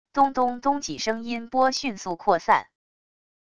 咚咚咚几声音波迅速扩散wav音频